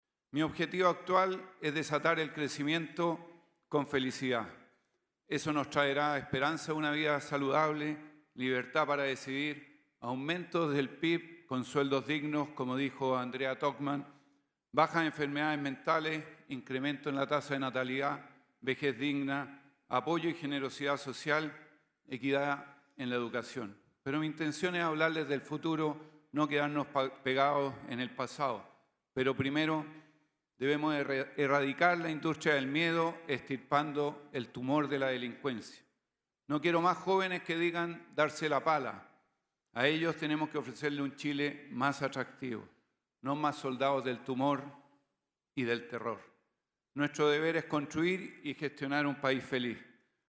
El también independiente Harold Mayne-Nicholls indicó ante el público que “es necesario erradicar la industria del miedo, extirpando el tumor de la delincuencia”.